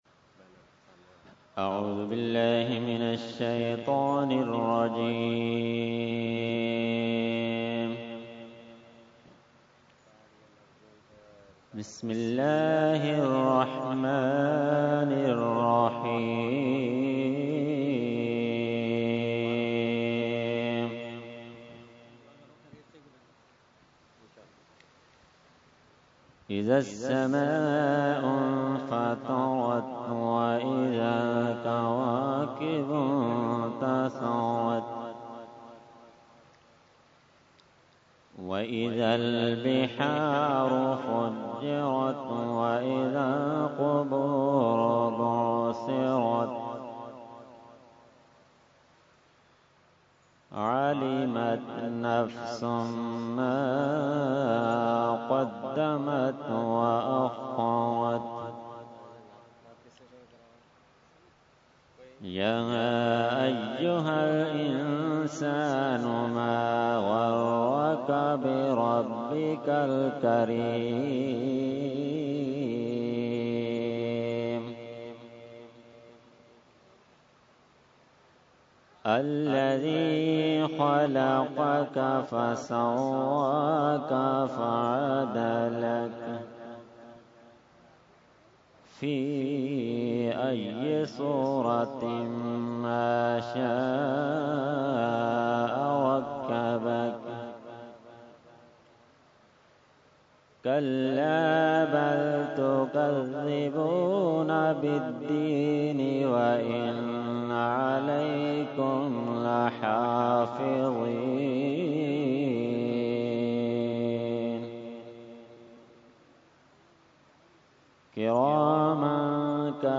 Qirat – Urs Ashraful Mashaikh 2016 – Dargah Alia Ashrafia Karachi Pakistan